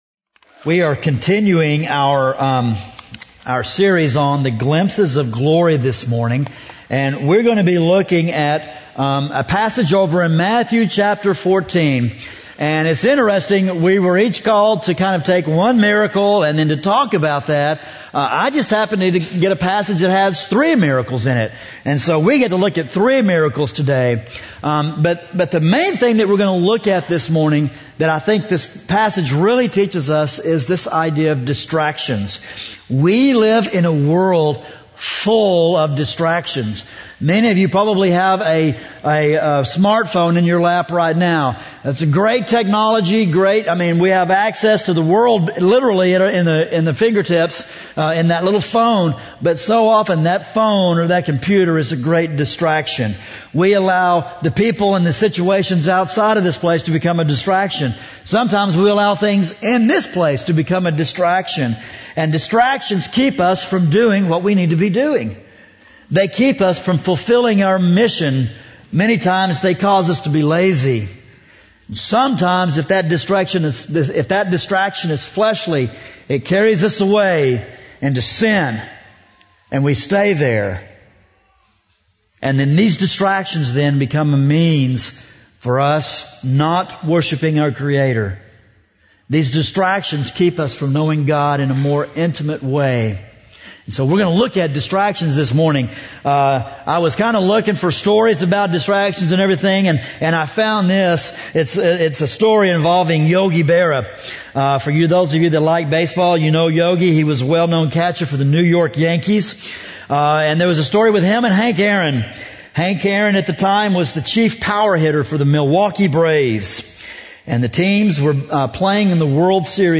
A message from the series “Glimpses of Glory.”